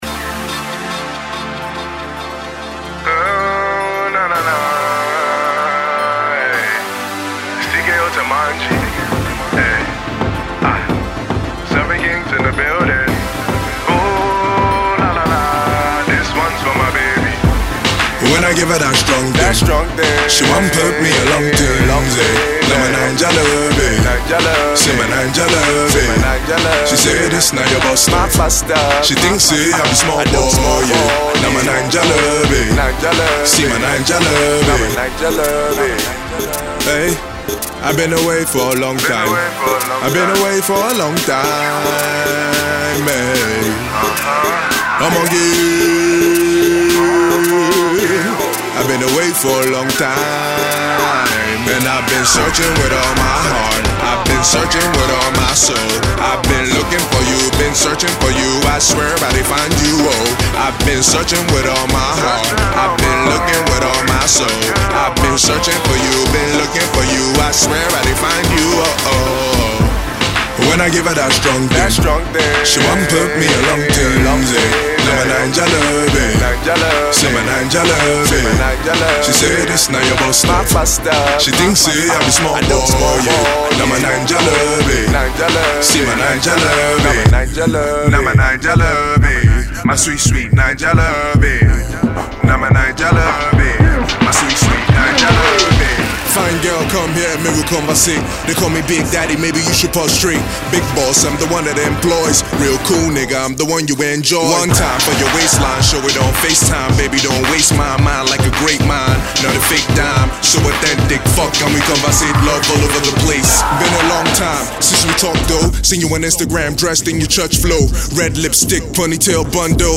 rapper
hip-hop